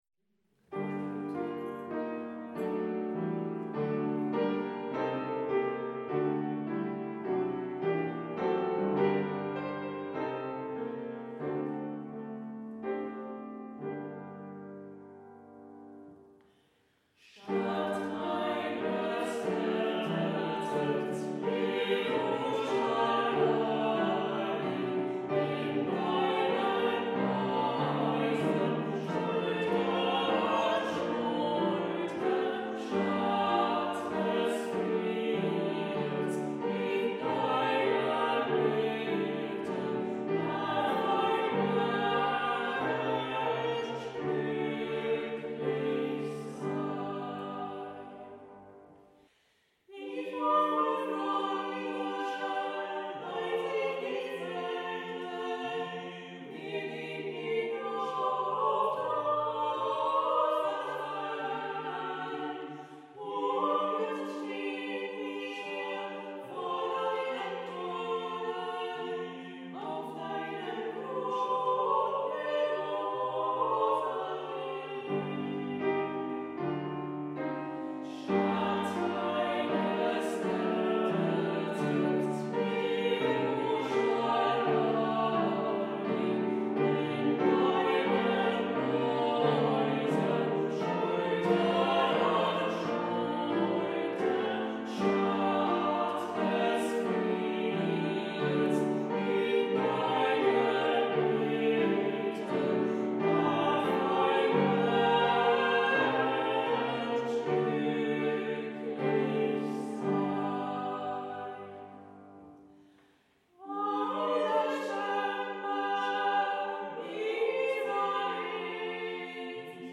2 Psalme
Ruprechtskirche